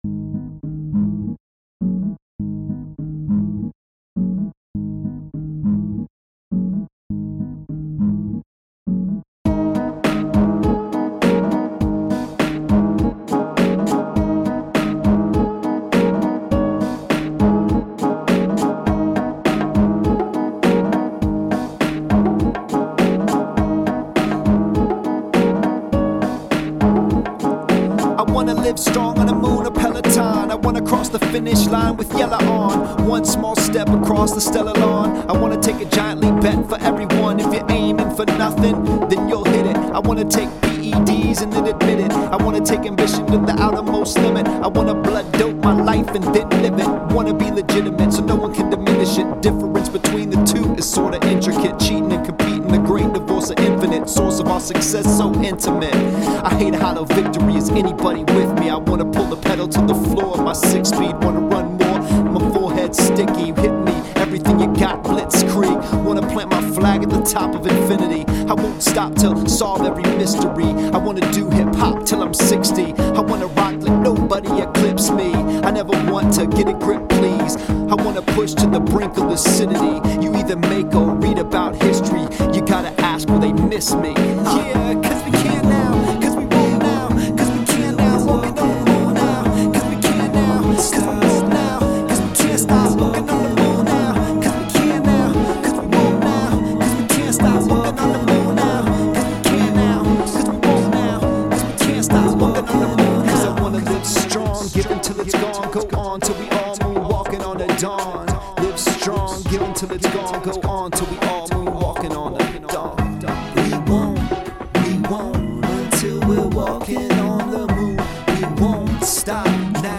Today’s song blog here: